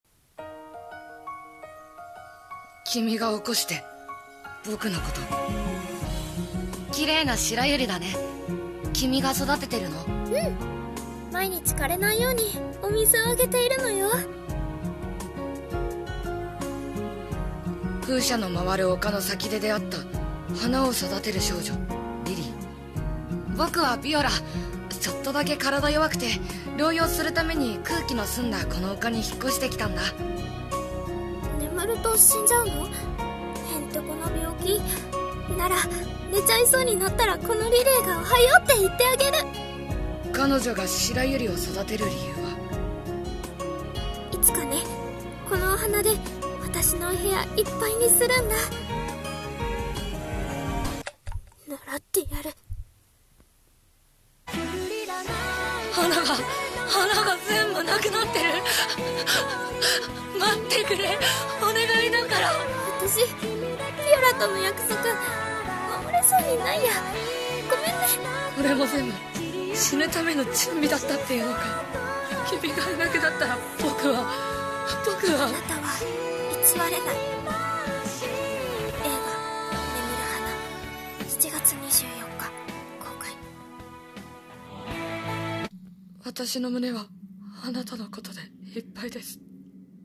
CM風声劇「眠る花」